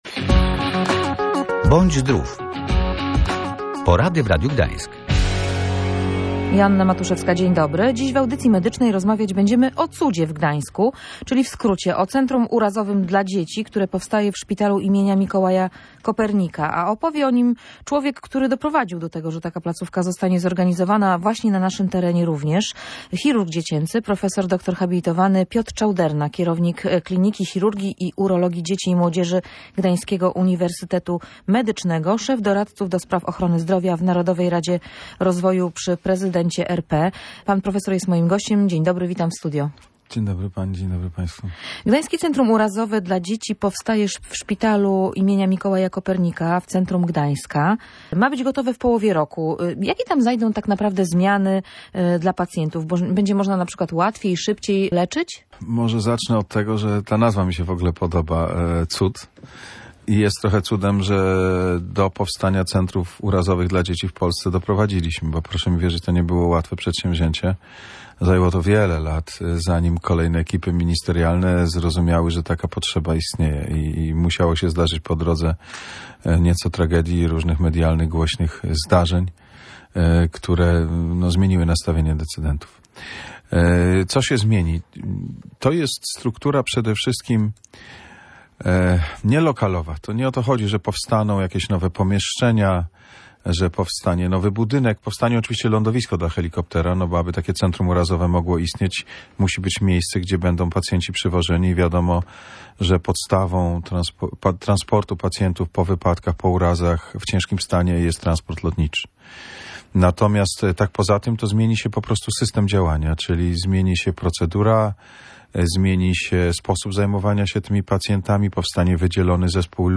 W audycji była tez mowa o Centrum Urazowym dla Dzieci, które w połowie roku ma ruszyć w szpitalu im. Mikołaja Kopernika w Gdańsku.